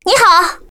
baobao_nan_nihao.mp3